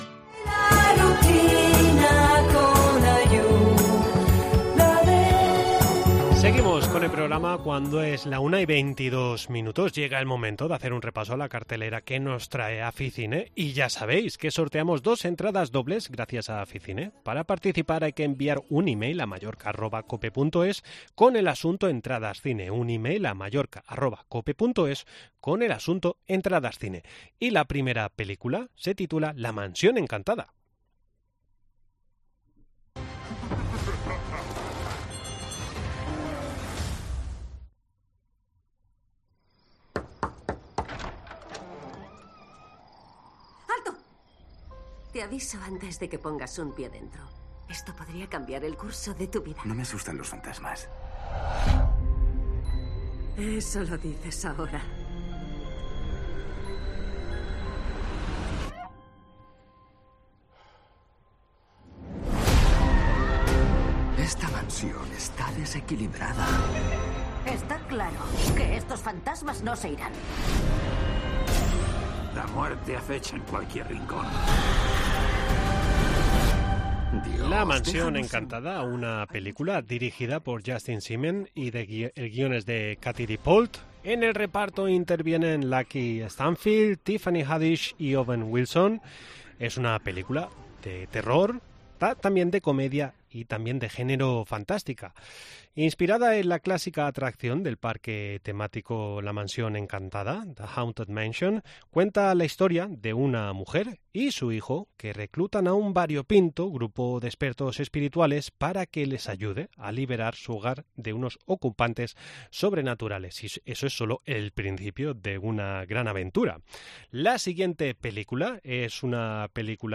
. Entrevista en La Mañana en COPE Más Mallorca, viernes 28 julio de 2023.